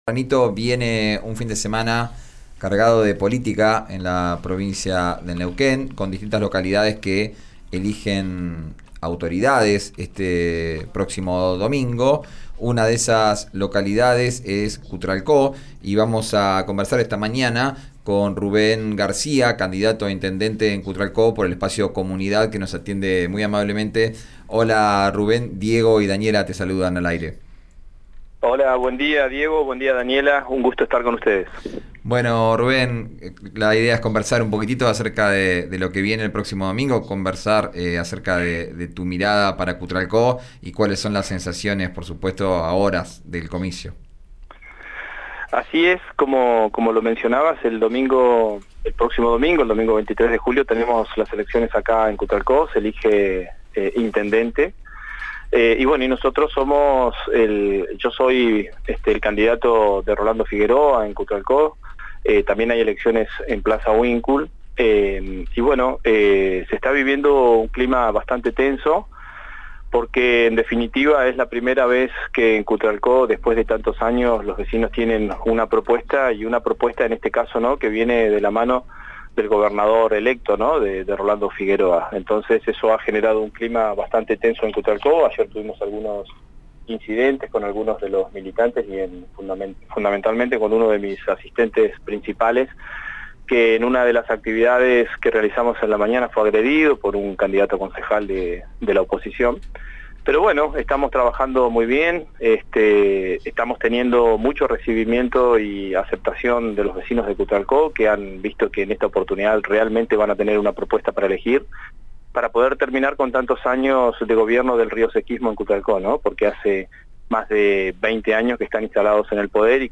Escuchá al candidato a intendente por el Frente de Todos por Cutral Co Ramón Rioseco en RÍO NEGRO RADIO: